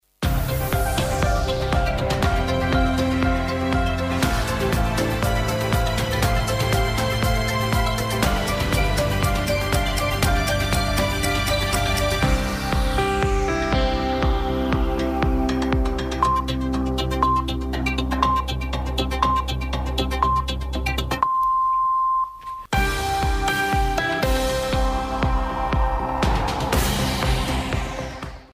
Sintonia de la cadena prèvia als senyals horaris